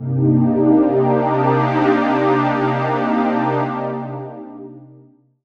Chord Funk 2.wav